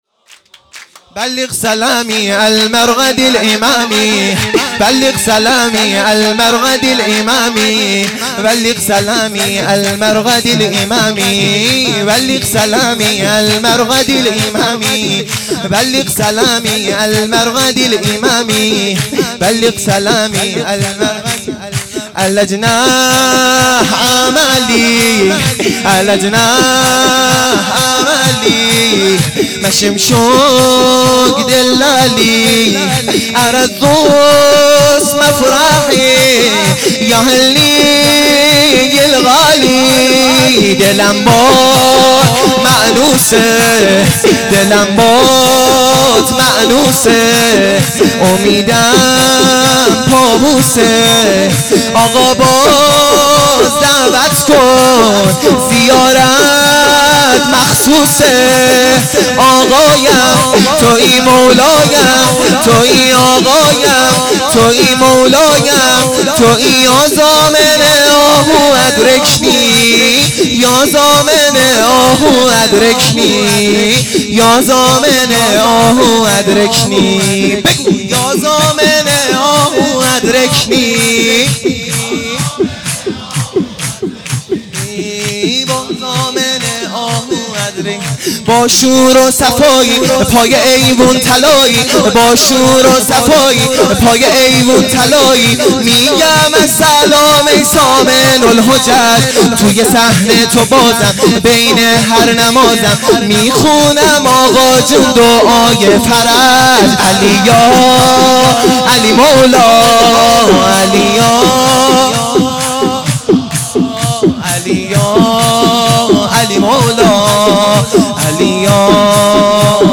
سرود ا بلغ سلامی المرقد الامامی
میلاد امام رضا علیه السلام